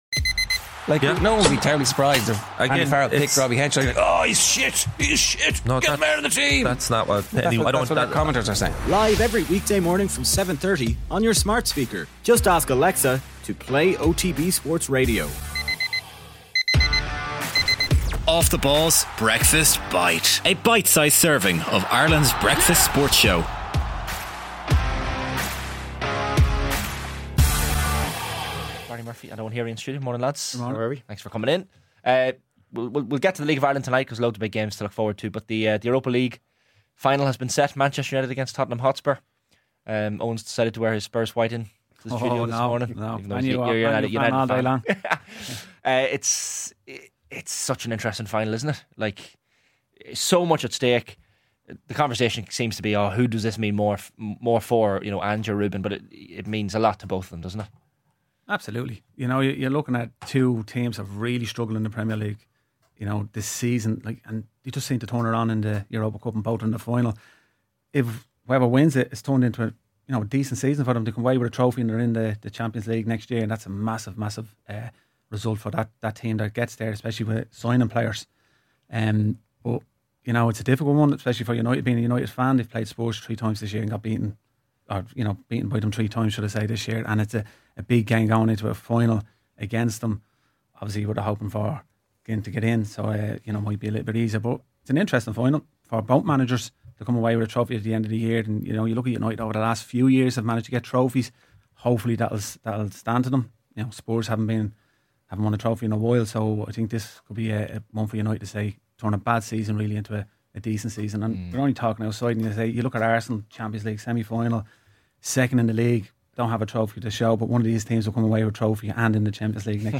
1 OTB Breakfast Bite | Quinlan tackles Lions selection + how Man Utd must focus on positive 10:33 Play Pause 1h ago 10:33 Play Pause Play later Play later Lists Like Liked 10:33 Welcome to Friday's OTB Breakfast Bite - your FREE bitesize sample of this morning's full Off The Ball Breakfast. On this morning's show, Alan Quinlan joined us in studio to give his view on Andy Farrell's Lions squad, and he looked ahead to this weekend's massive URC interpro.